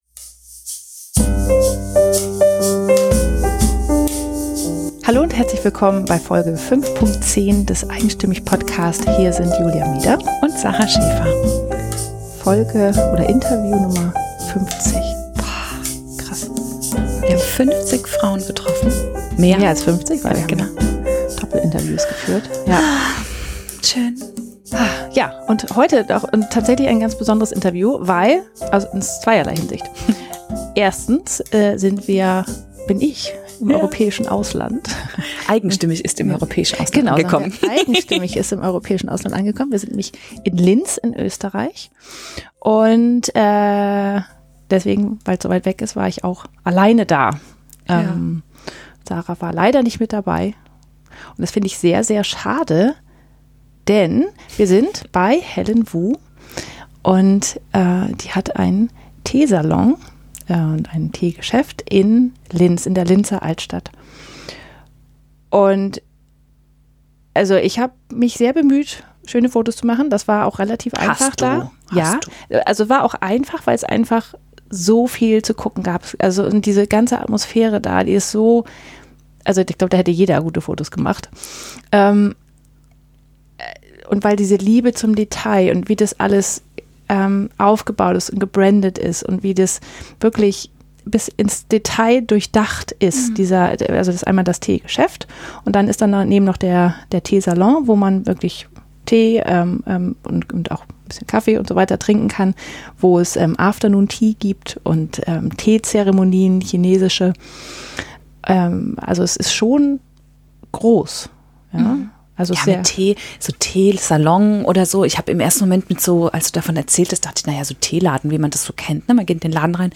Sie ist der Meinung, dass man für das Glück in seinem Leben selbst Verantwortung trägt. 48 Minuten 38.77 MB Podcast Podcaster eigenstimmig Interviews mit einzigartigen Frauen*, die lieben, was sie tun.